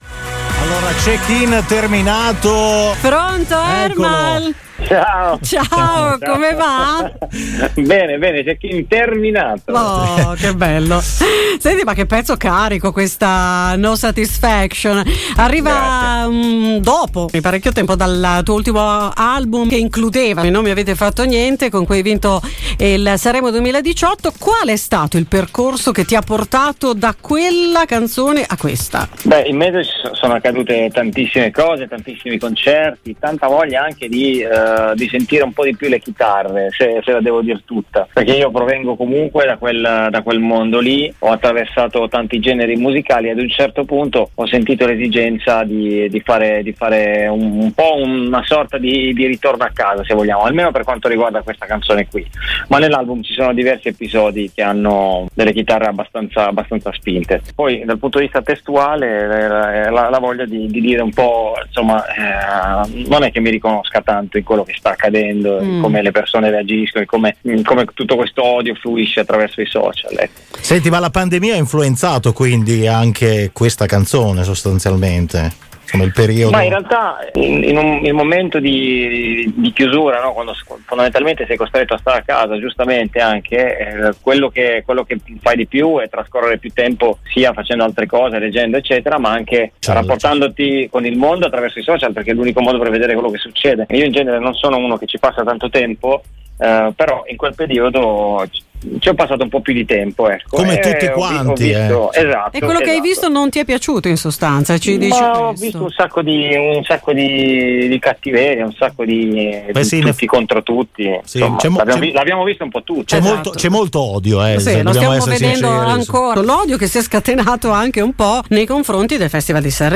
“Non amo la stabilità” l’intervista di Radio Pico a Ermal Meta